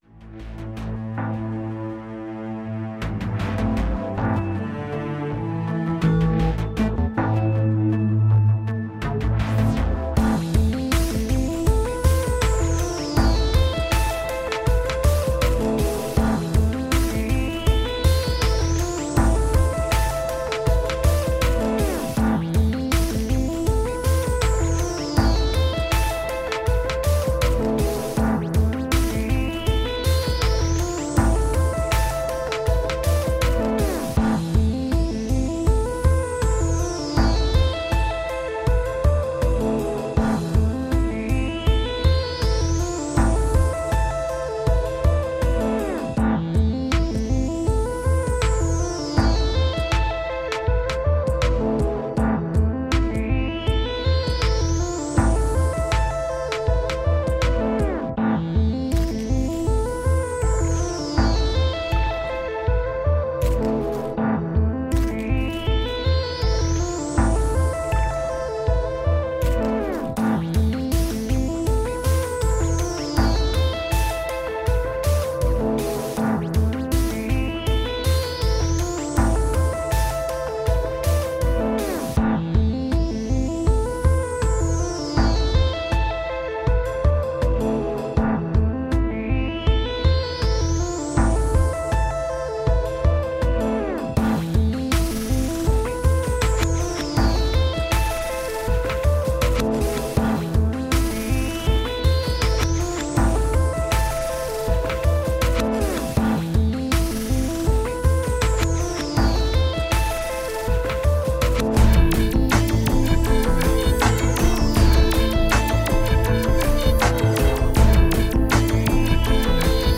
Morningstar (Soundtrack-Electronic)